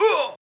damage2.wav